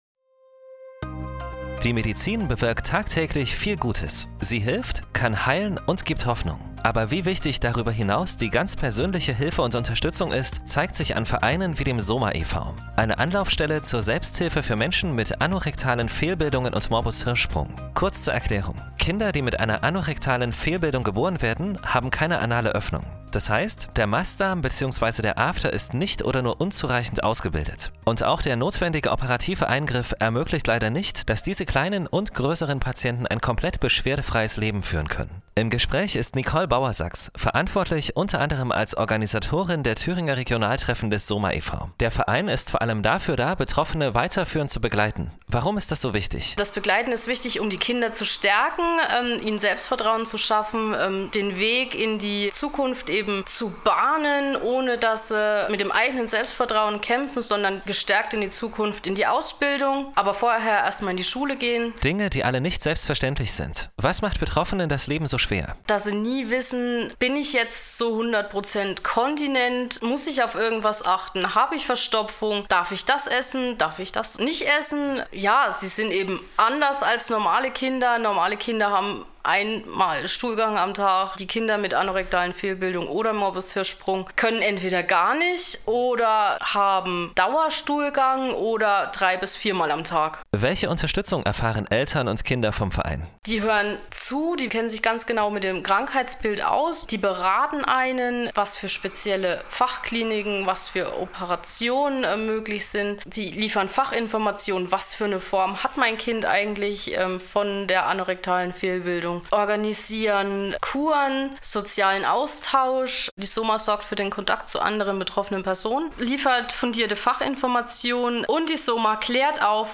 Interview mit einem SoMA-Mitglied
audio-interview-soma-mitglied-radio.mp3